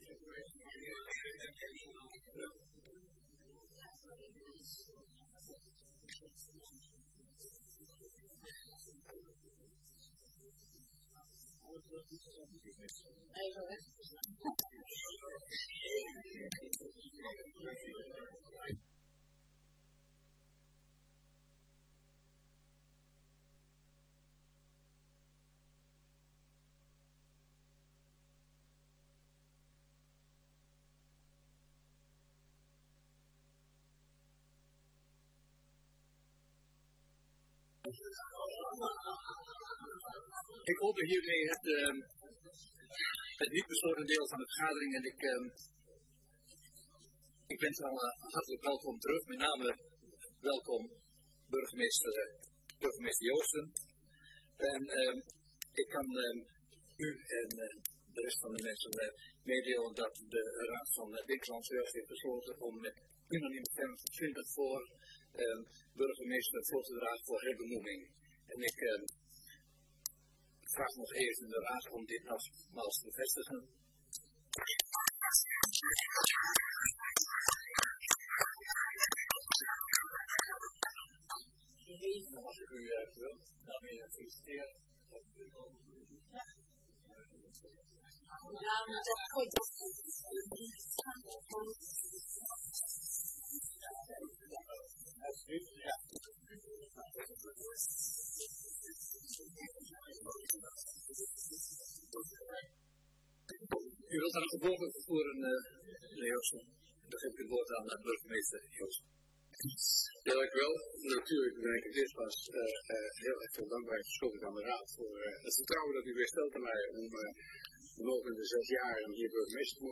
Download de volledige audio van deze vergadering
Raadzaal